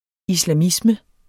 Udtale [ islaˈmismə ]